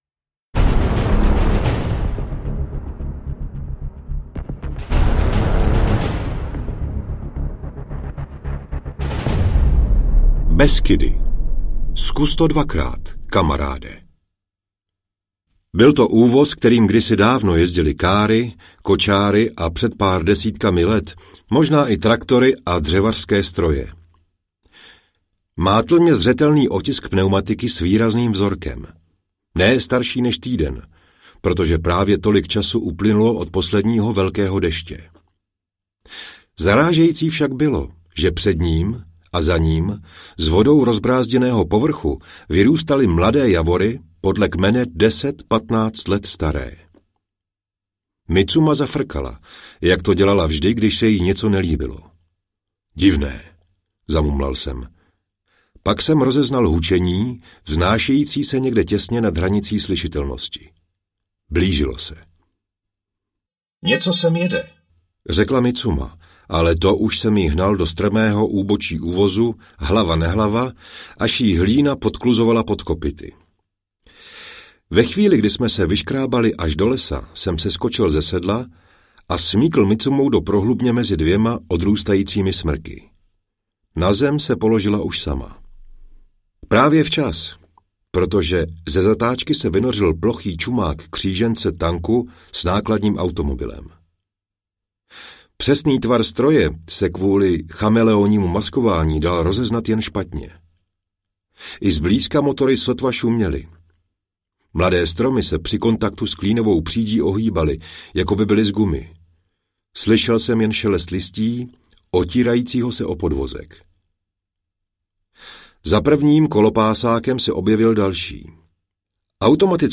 Drsný spasitel 2 (dokončení) audiokniha
Ukázka z knihy